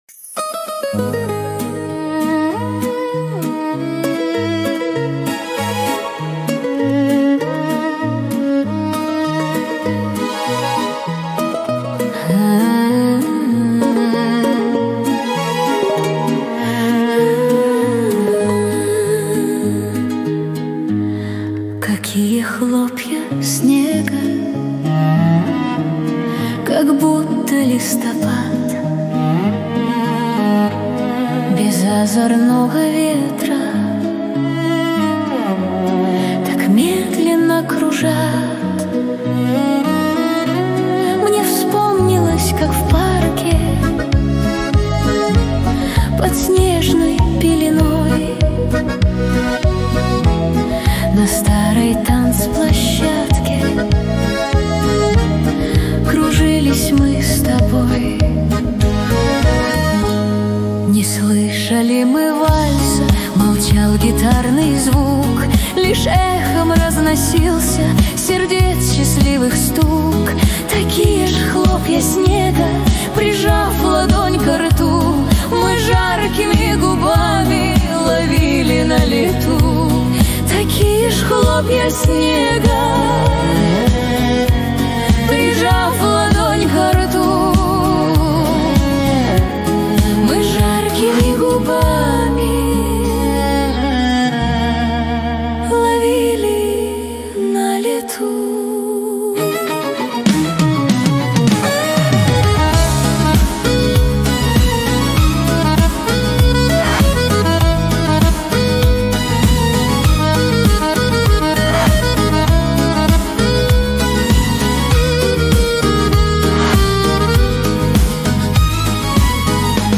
Русская AI музыка